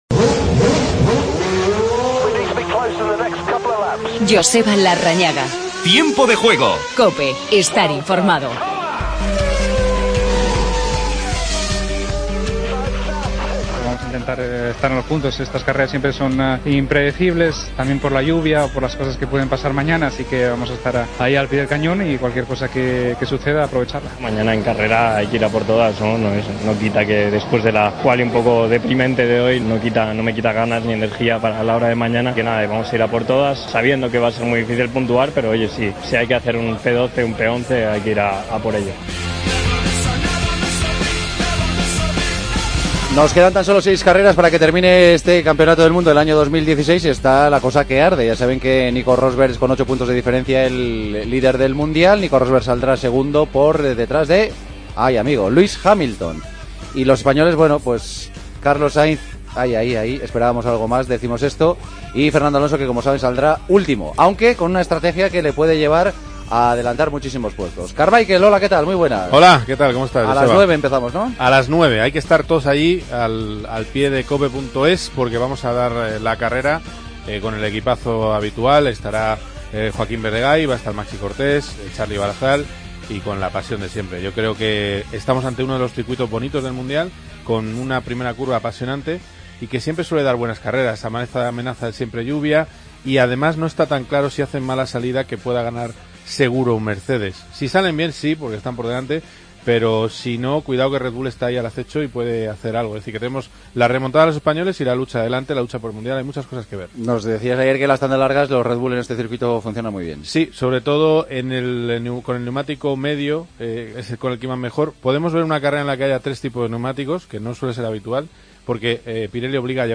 Entrevista a Roberto Merhi.